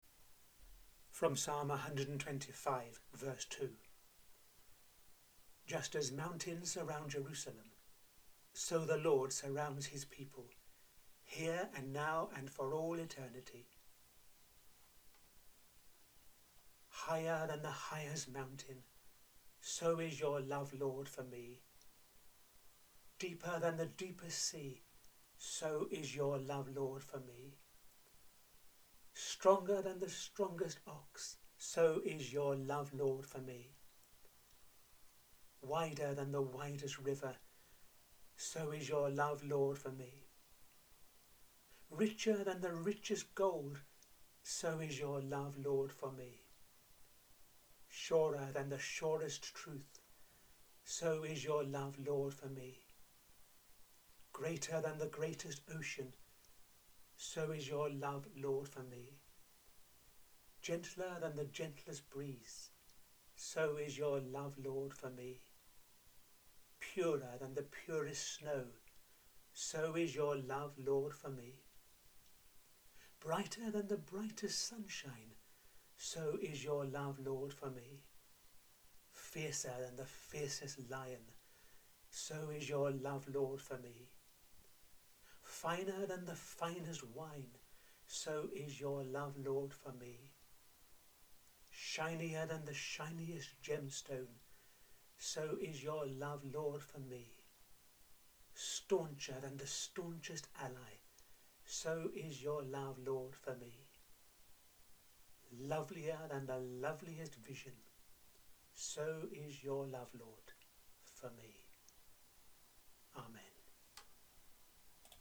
The sixteenth prayer in the audio series I’m running over these few weeks: